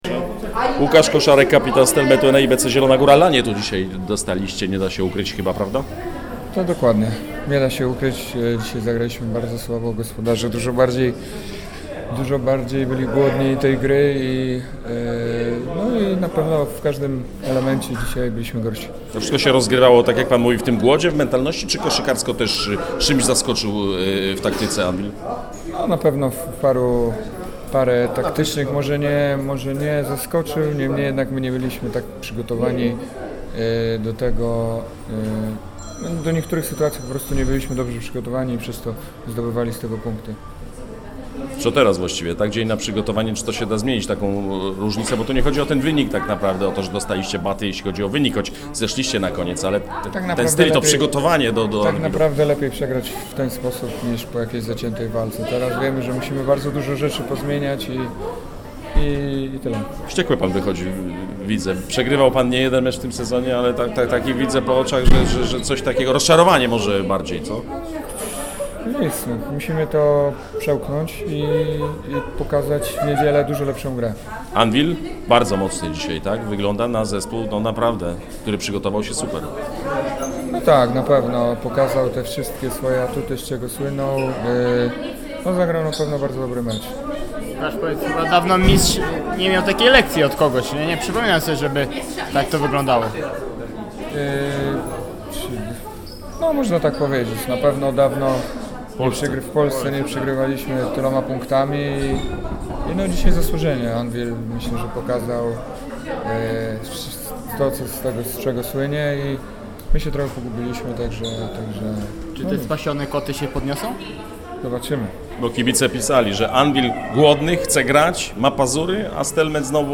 '-Zagraliśmy bardzo słabo, do części sytuacji nie byliśmy przygotowani - mówi po meczu z Anwilem Włocławek kapitan Stelmetu ENEI BC